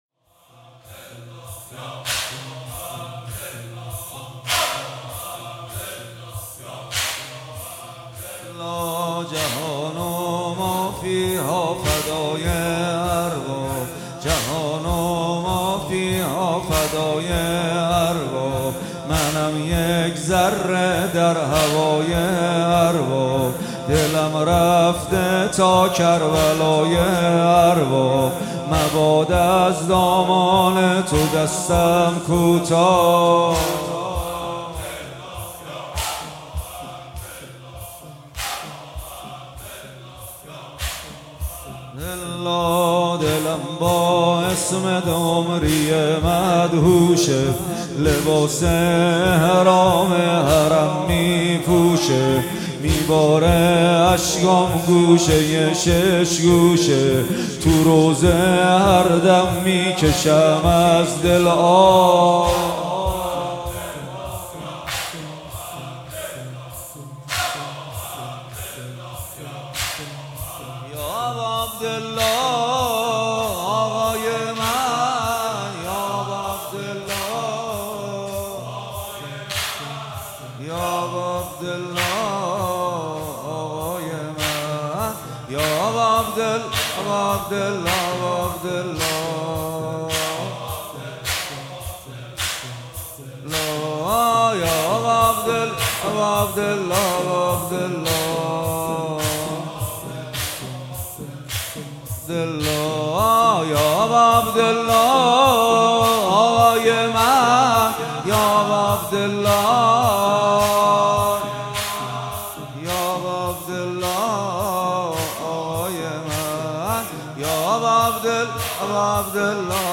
با رادیو عقیق همراه شوید و مداحی جهان و مافی‌ها فدای ارباب را به صورت کامل بشنوید. مداحی جهان و مافی‌ها فدای ارباب توسط محمد حسین پویانفر در مجلس ریحانه النبی | 8 خرداد | 1403 اجراشده. مداحی به سبک تک اجرا شده است.